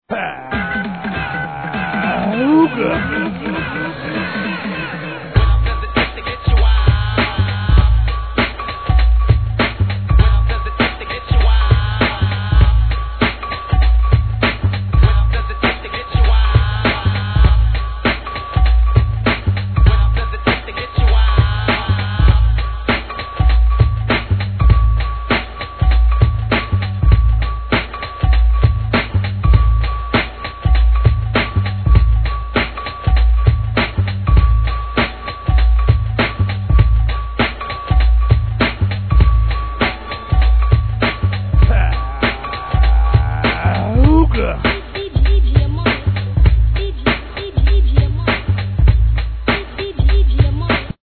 HIP HOP/R&B
ブレイクビーツ仕様!